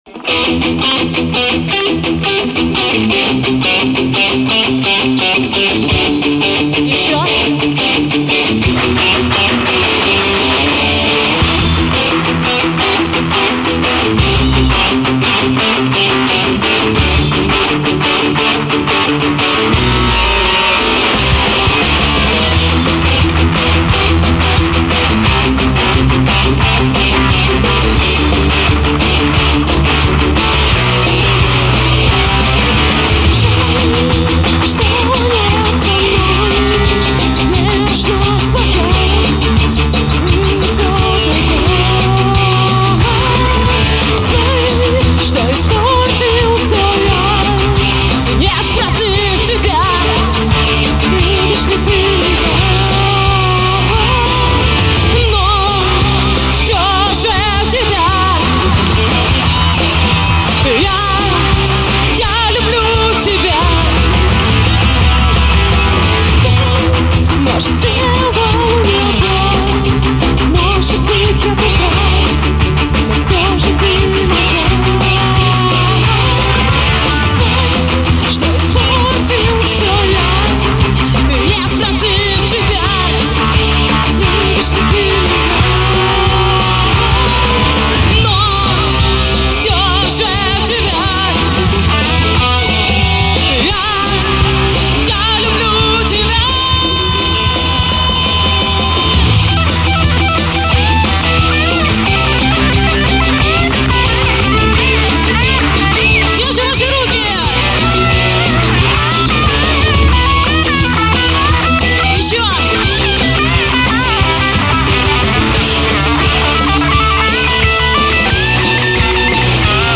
Потому не судите строго-это не студийные вылизанные записи-это как в жизни- по всякому.
Панки тоже имеют право быть услышанными.